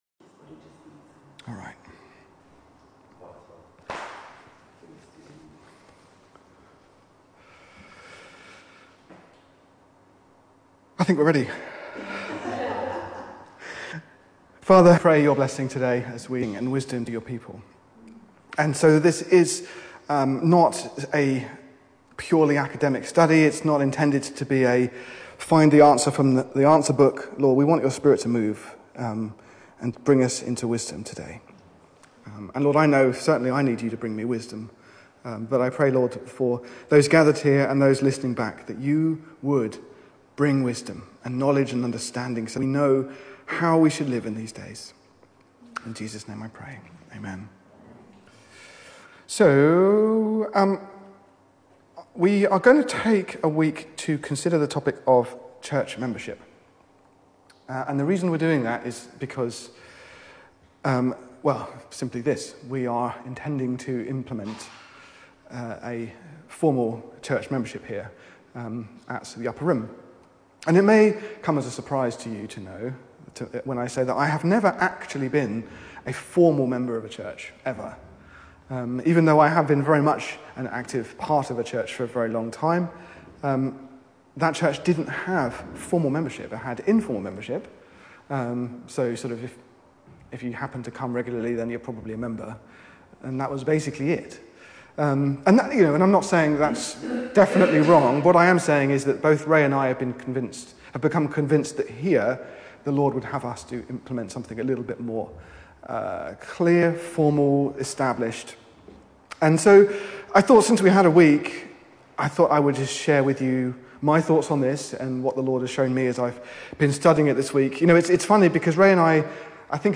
If you want to hear more about this, take a listen to a sermon on the topic here.